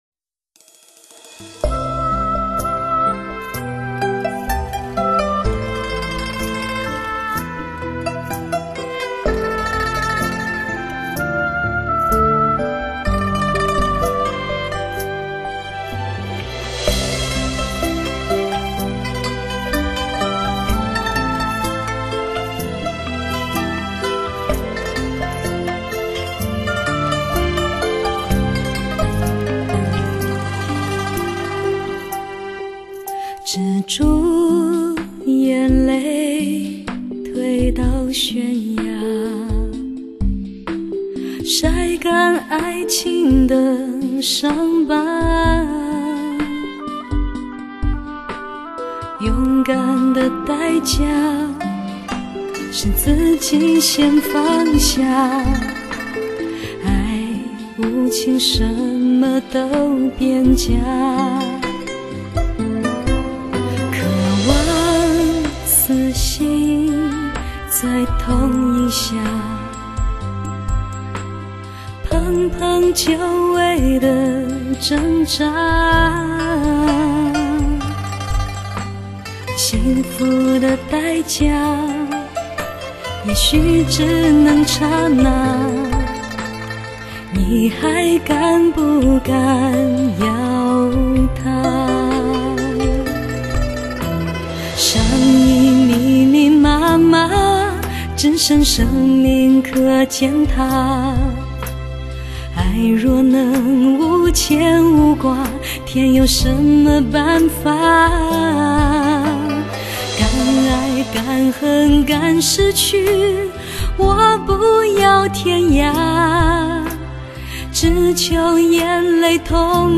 [mjh4][light]那歌声...那旋律...悠扬飘荡...[/light][/mjh4]